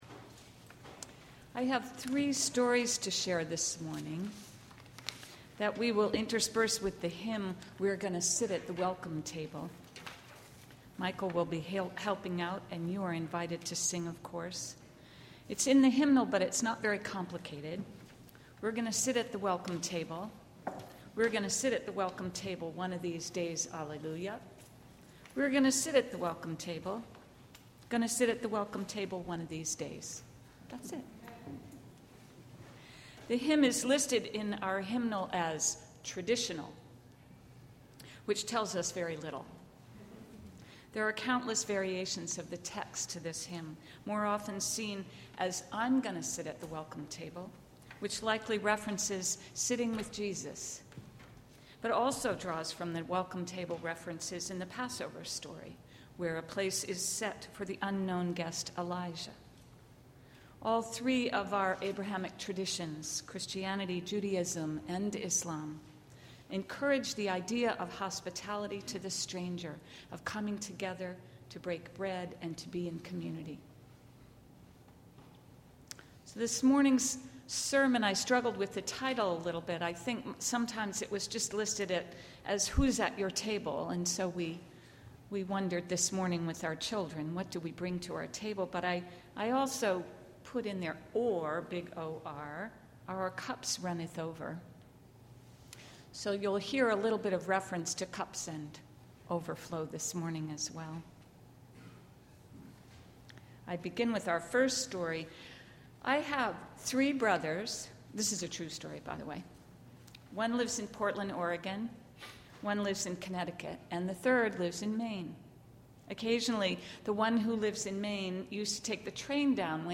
The Theodore Parker House Band provides music.